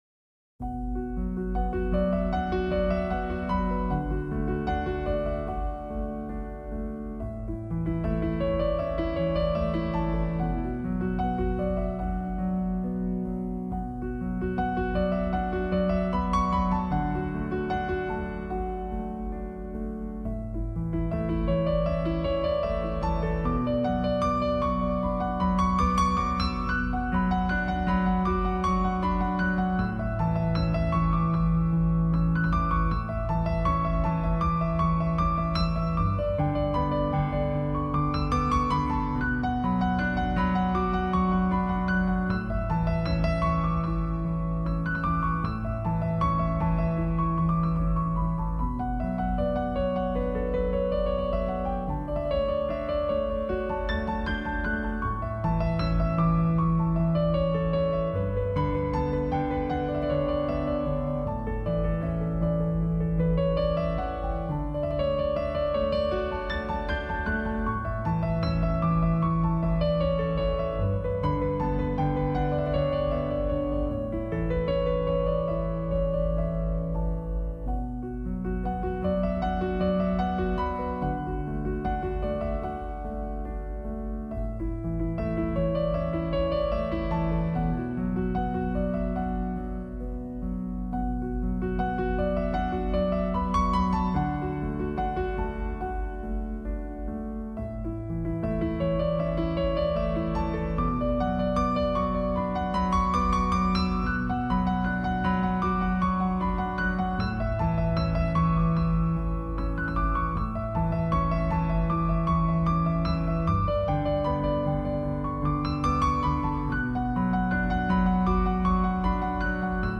专辑语种：纯音乐